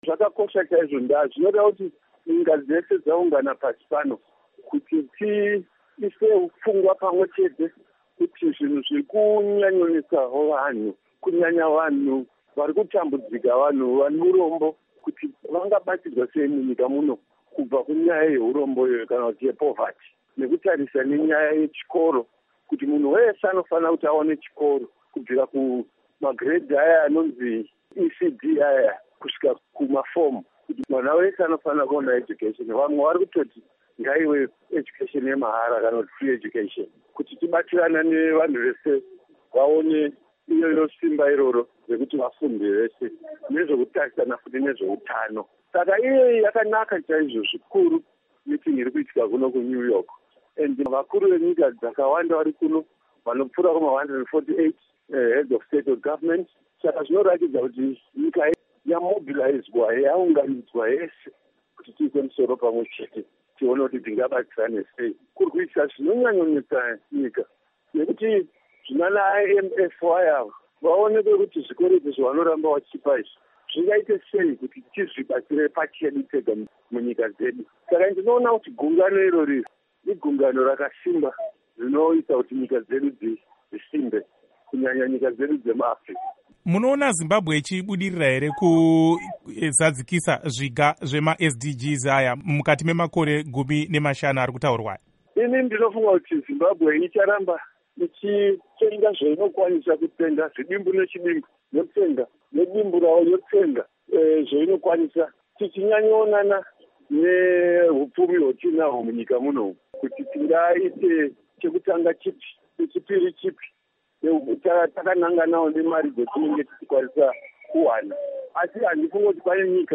Hurukuro naDoctor David Parirenyatwa